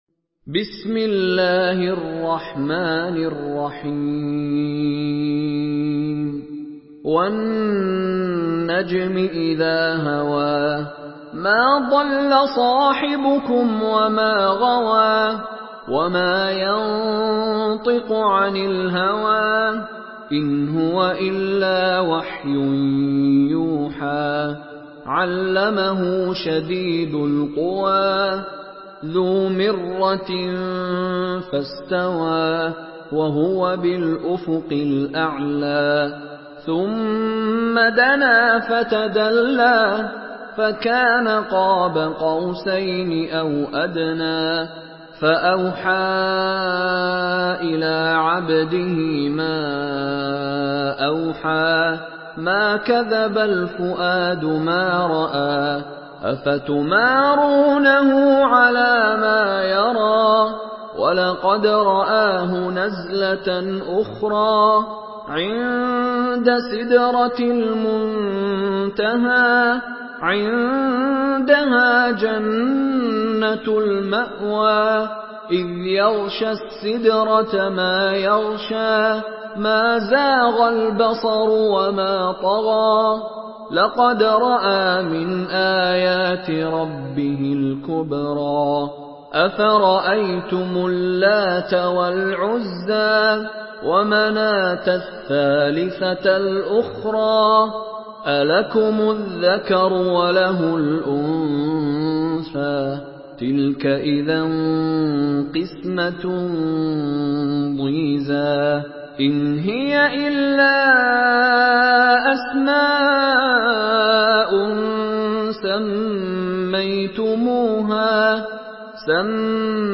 Surah আন-নাজম MP3 by Mishary Rashid Alafasy in Hafs An Asim narration.
Murattal Hafs An Asim